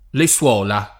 suolo [SU0lo] s. m. — nel sign. (tosc.) di «suola (delle scarpe)», pl. le suola [